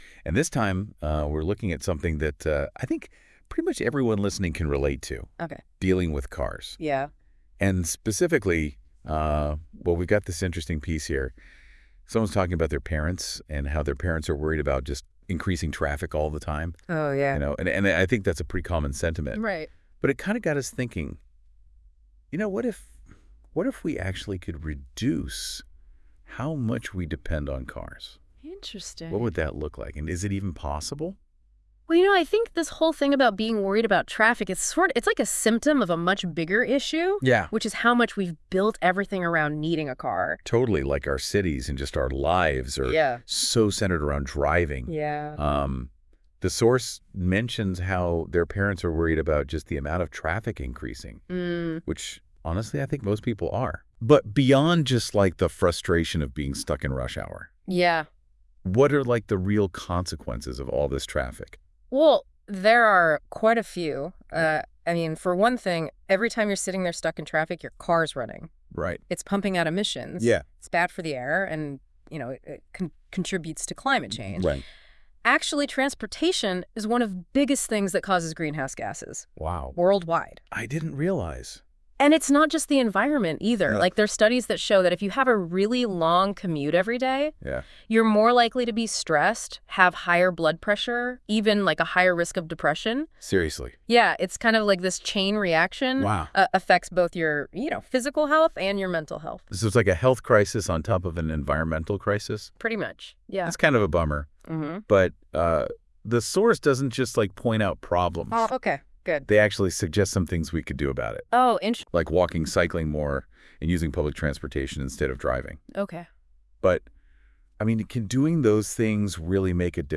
Tiếng Anh 9 – Unit 1: Local communication – Dialogue 5